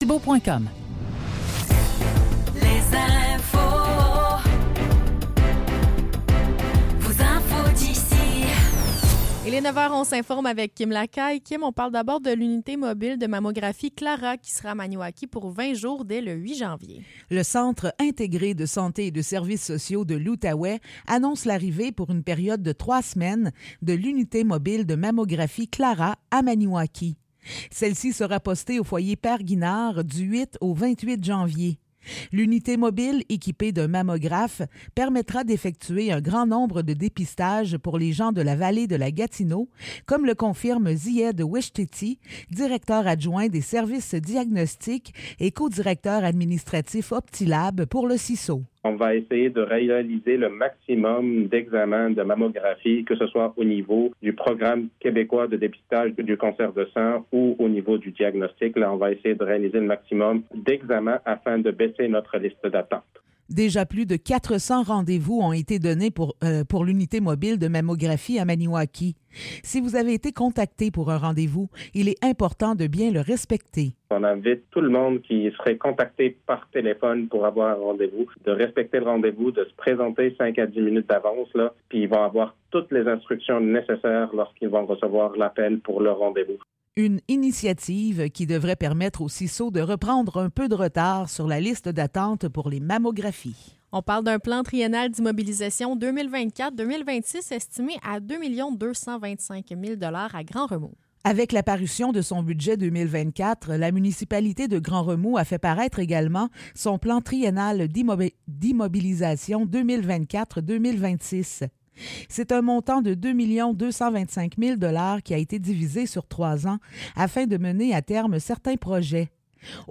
Nouvelles locales - 2 janvier 2024 - 9 h